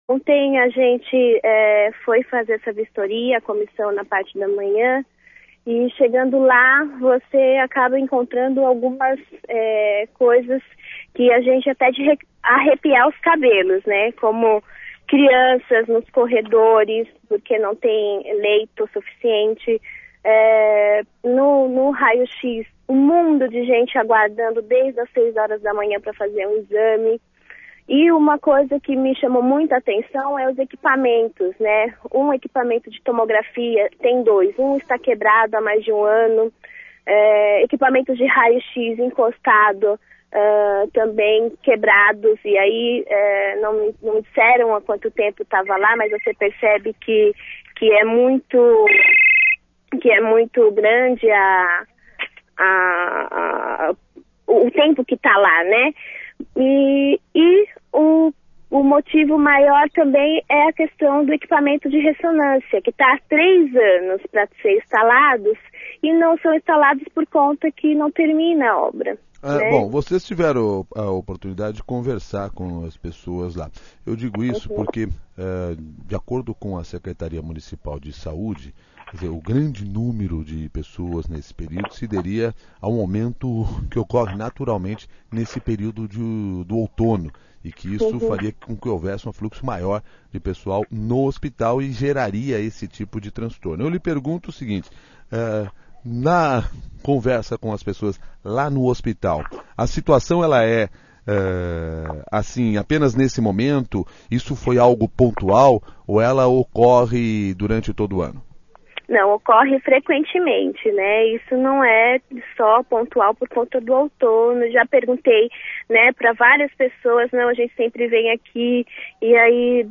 Ouça a entrevista da vereadora de São Paulo Juliana Cardoso (PT), presidente da Comissão de Saúde da Câmara Municipal, ao CBN SP